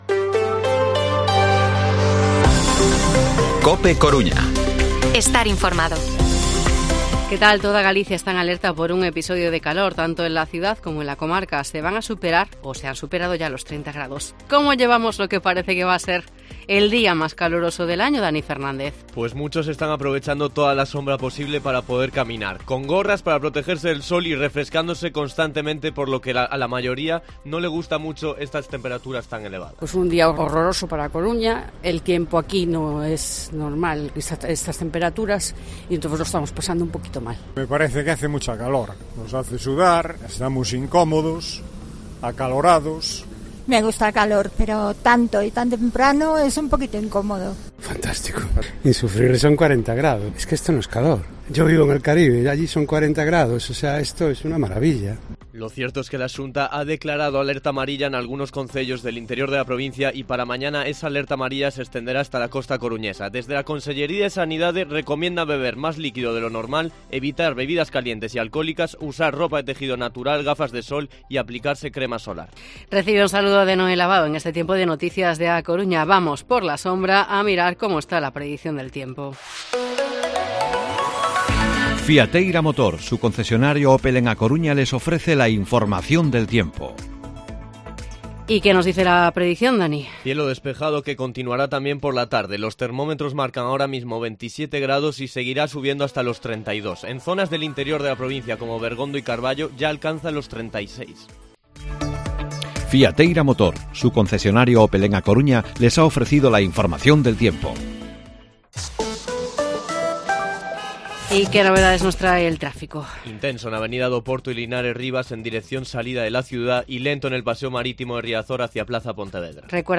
Informativo Mediodía COPE Coruña martes, 22 de agosto de 2023 14:20-14:30h